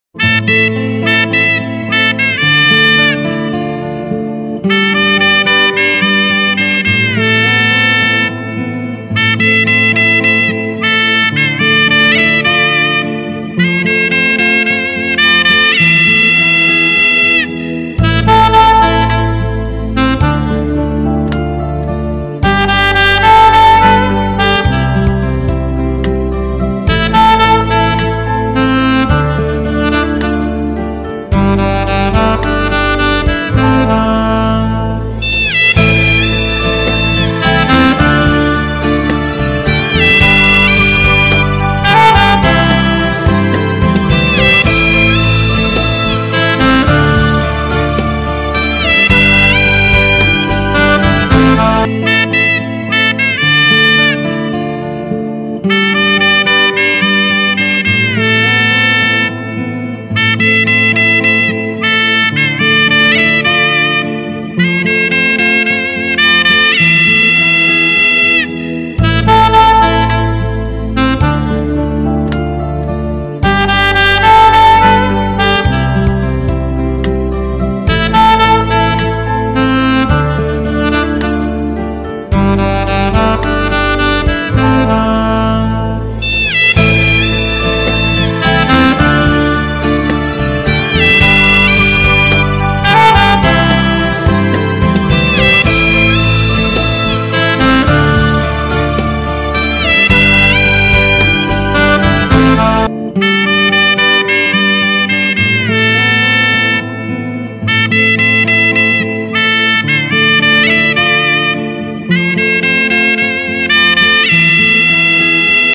cực phẩm âm hưởng dân gian, giai điệu réo rắt và ấn tượng